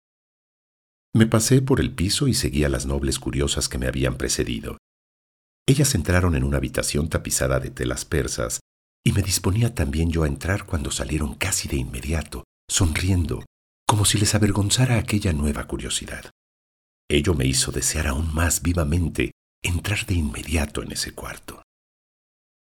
Mexican voice talent